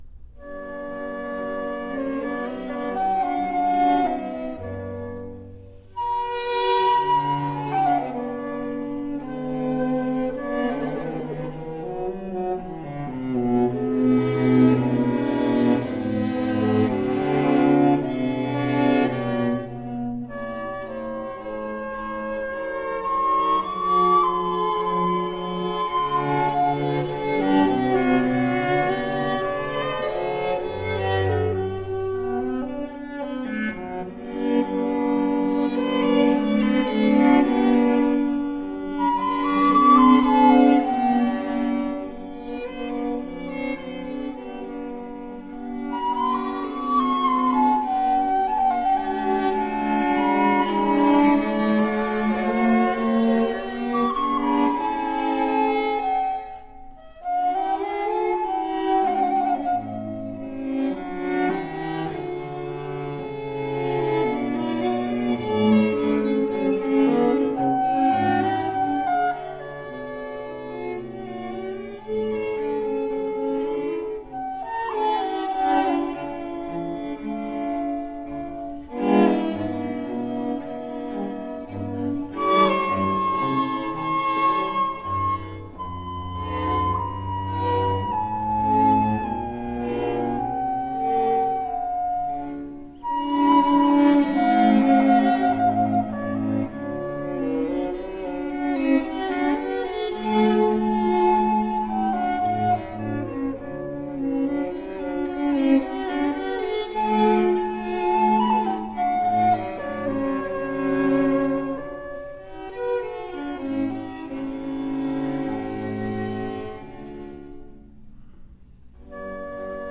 Spectacular baroque and classical chamber music.
Classical, Classical Period, Orchestral, Instrumental, Cello
Flute, Harpsichord, Oboe, Violin